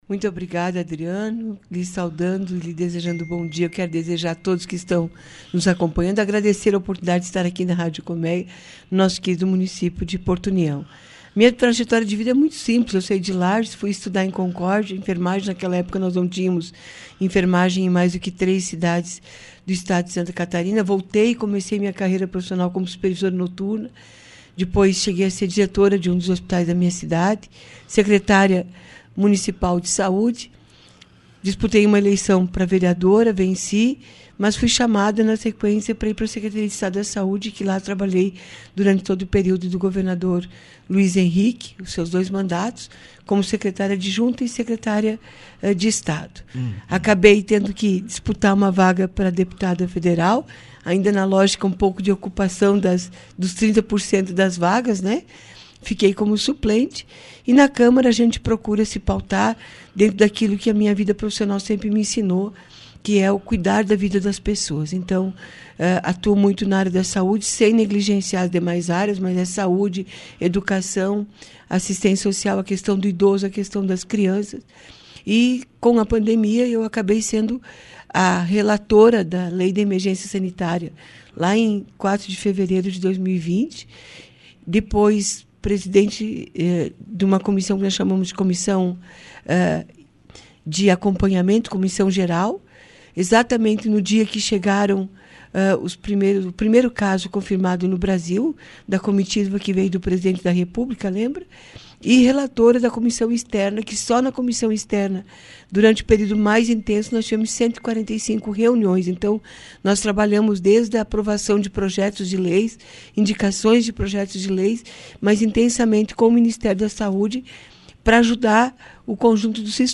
Nessa quinta-feira, 28, a Deputada Federal por Santa Catarina, Carmen Zanotto, participou do Jornal da Manhã da Rádio Colmeia. Em pauta, a deputada falou sobre seu trabalho em prol da saúde, da valorização dos profissionais da área e também dos projetos em tramitação como a PL 2564, que cria o piso salarial da enfermagem.
Você pode acompanhar a entrevista completa com a deputada abaixo: